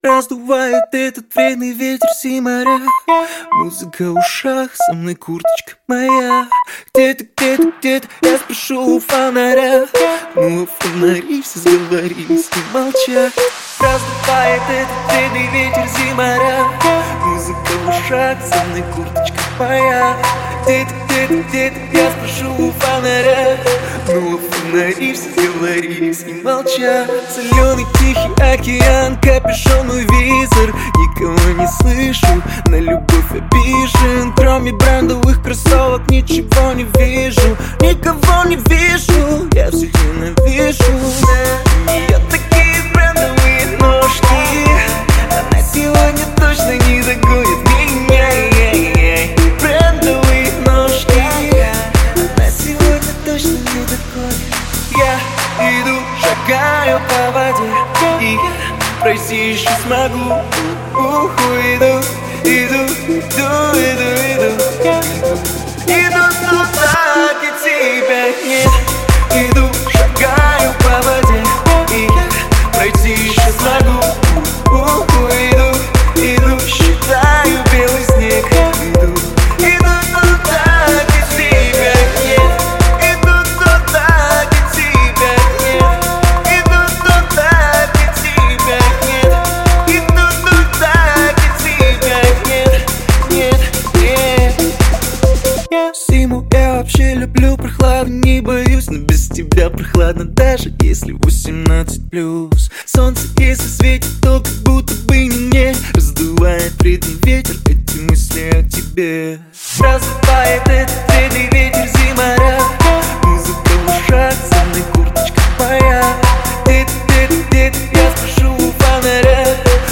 Жанр: Хип-хоп / Русский рэп